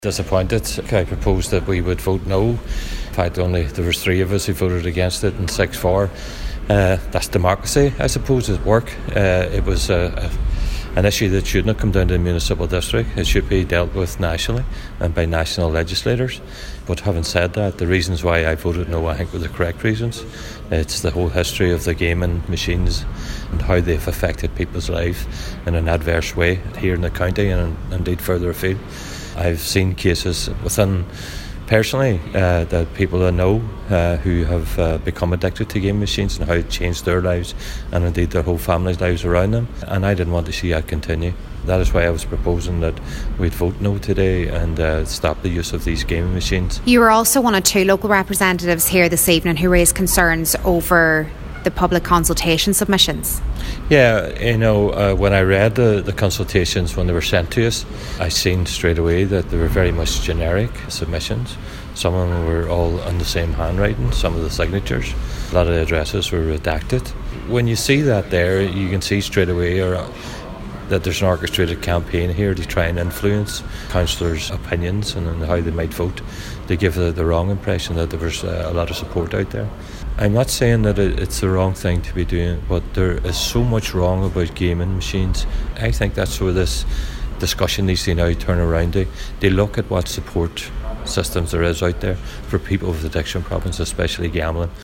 Cllr Gerry McMonagle voted against the motion, he believes that the decision made is a huge step backwards: